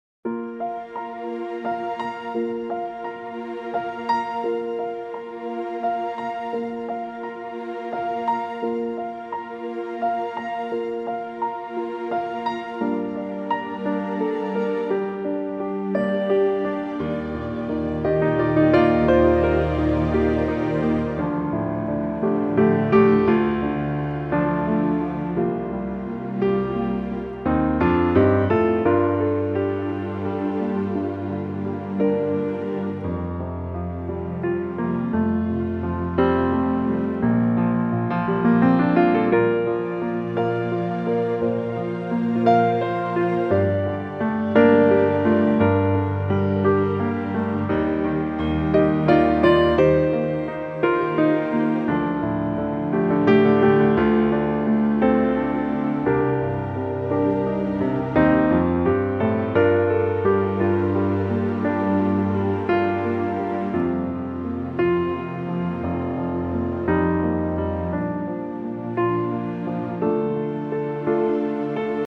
key - Bb to Db - vocal range - G to Eb